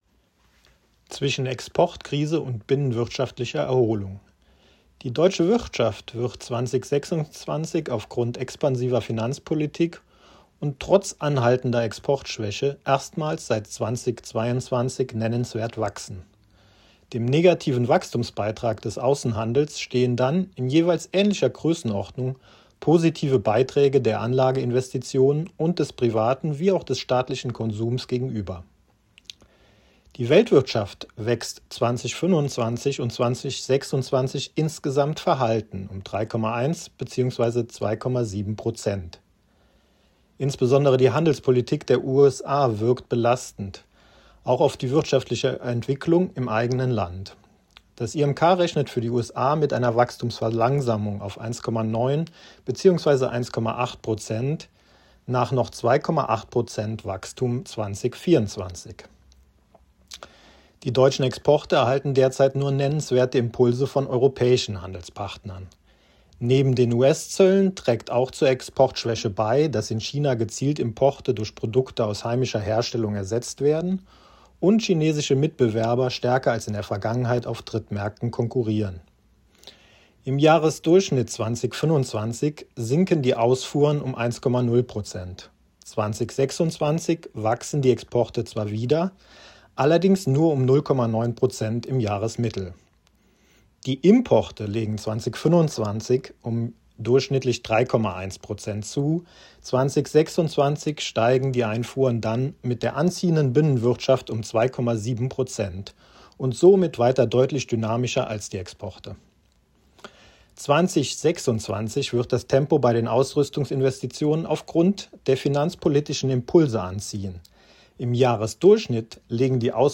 Kernergebnisse der Prognose im Audio-Statement